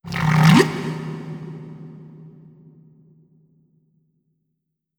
khloCritter_Male12-Verb.wav